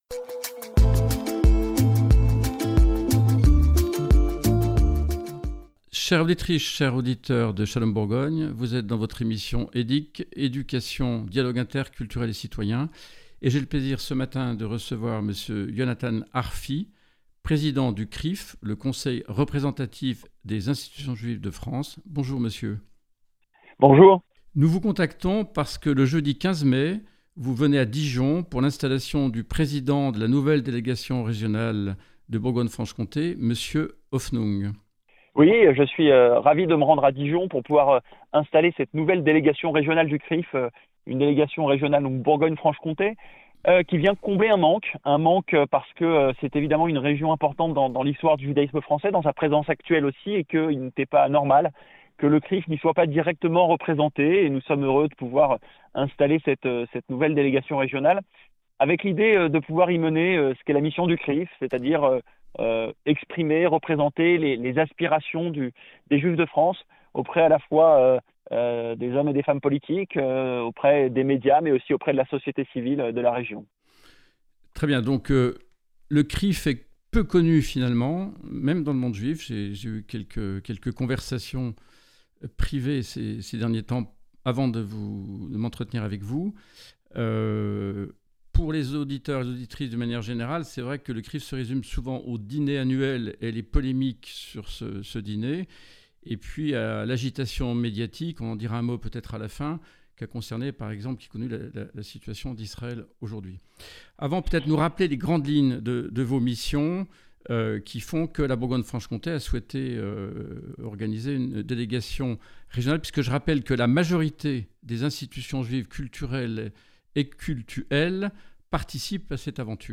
Entretien Yonathan ARFI - Président du CRIF